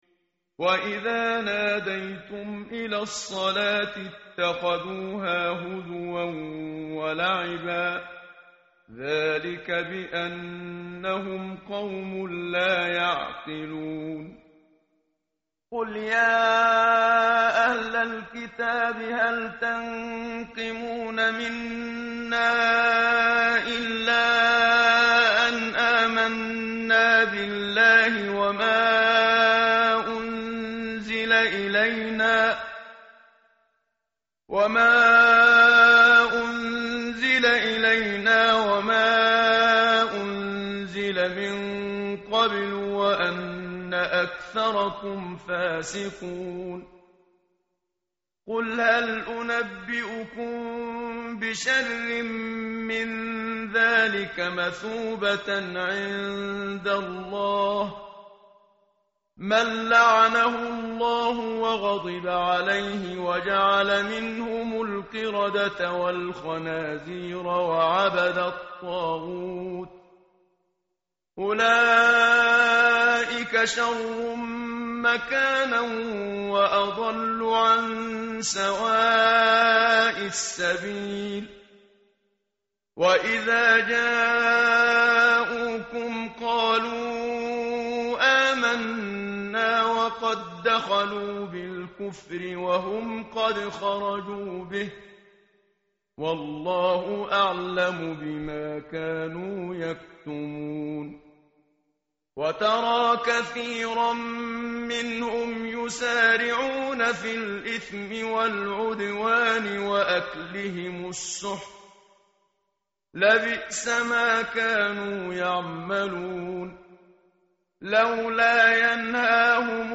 tartil_menshavi_page_118.mp3